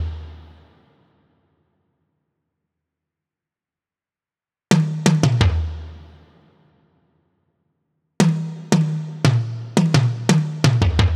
Index of /DESN275/loops/Loop Set - Futurism - Synthwave Loops
BinaryHeaven_86_Toms.wav